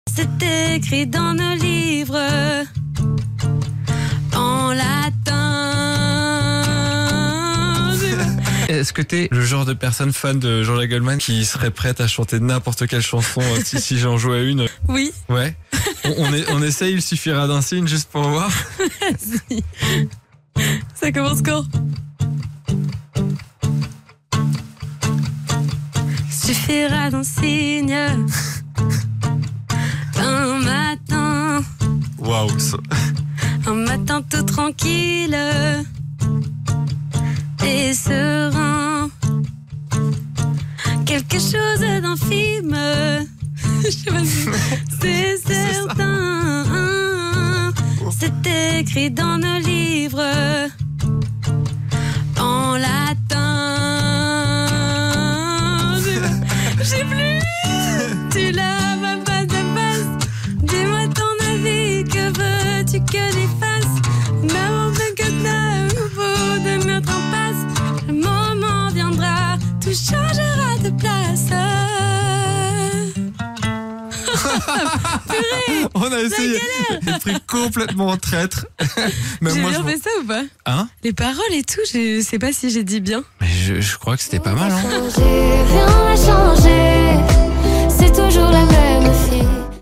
une reprise